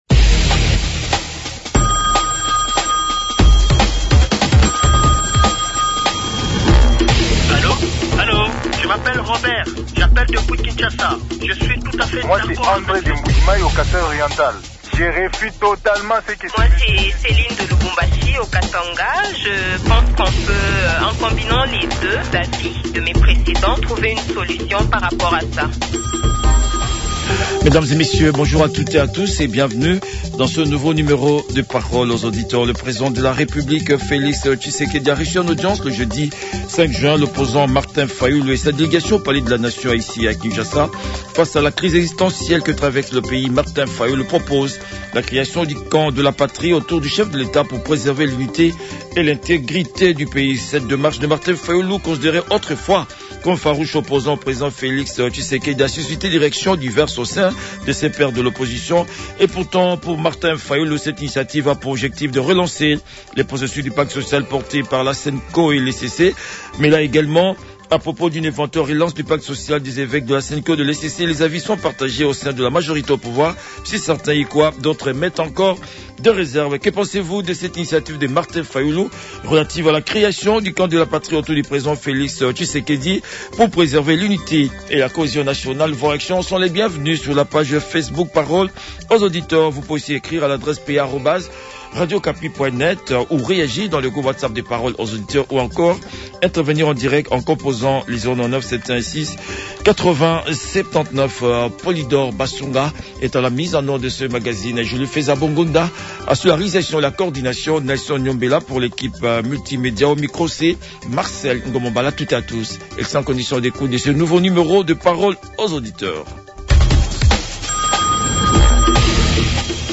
Les auditeurs ont débattu avec Joseph Nkoy, député national élu de Katako-Kombe dans le Sankuru, et rapporteur adjoint de la commission Défense et Sécurité à l’Assemblée nationale